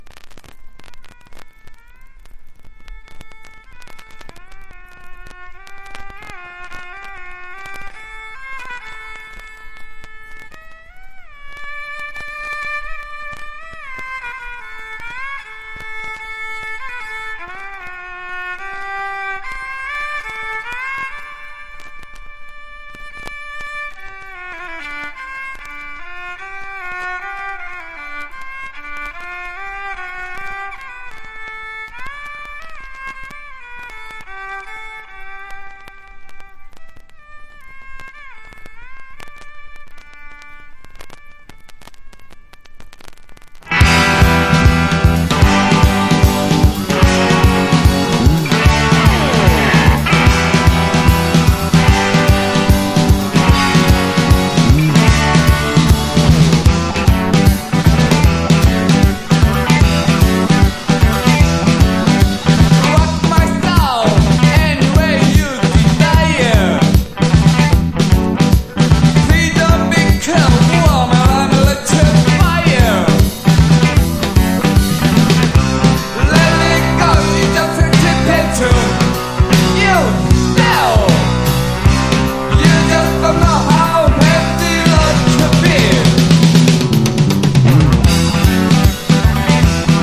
中華風プログレッシブかつサイケなロック作品!